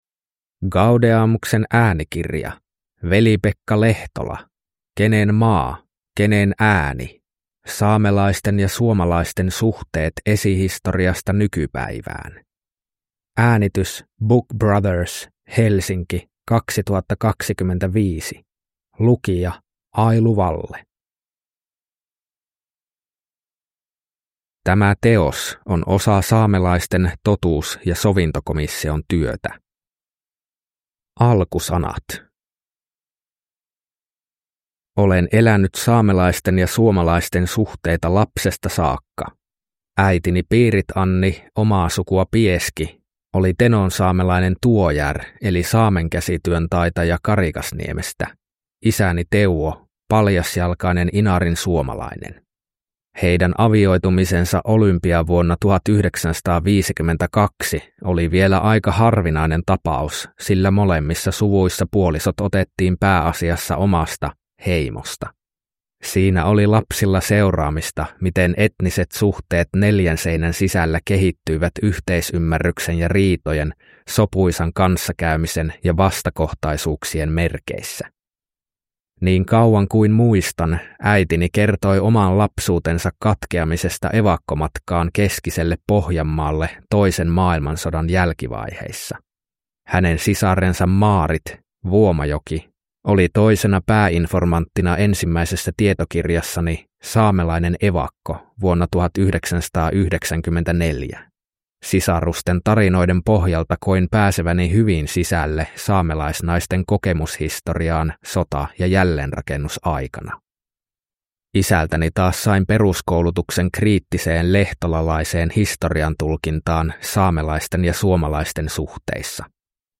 Kenen maa, kenen ääni? – Ljudbok